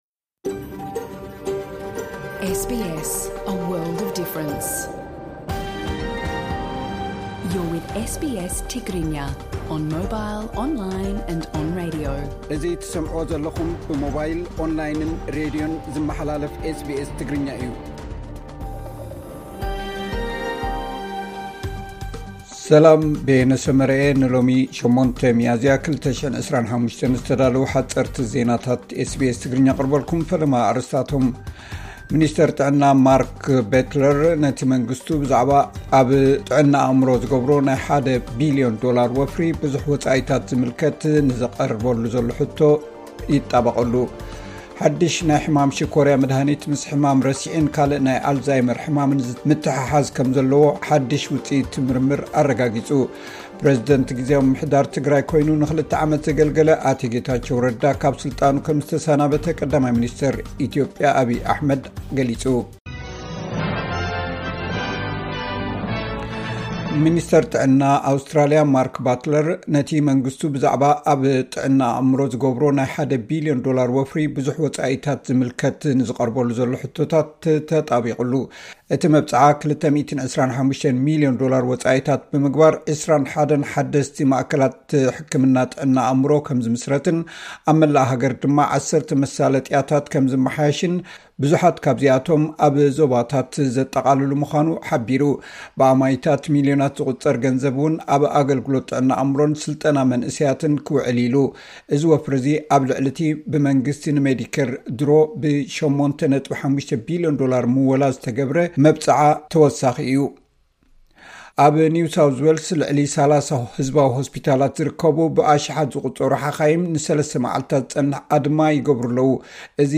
ሓጸርቲ ዜናታት ኤስ ቢ ኤስ ትግርኛ (08 ሚያዝያ 2025)